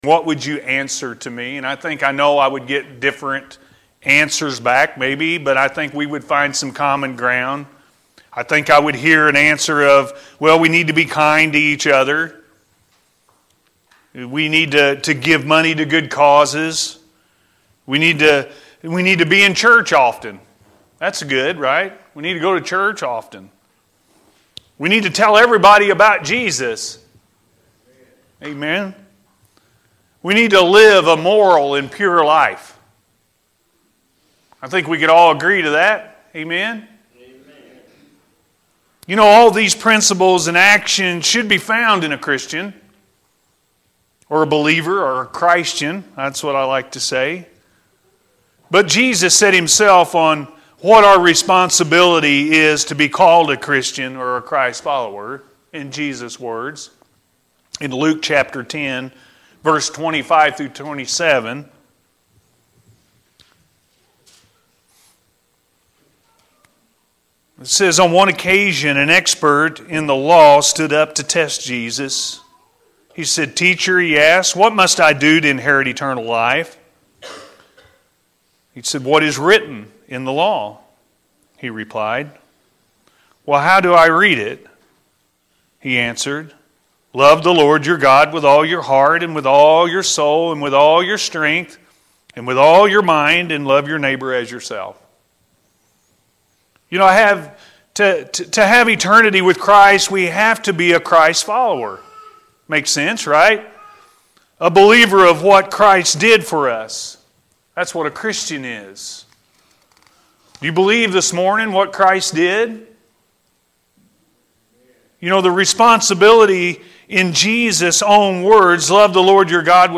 Be A Good Samaritan-A.M. Service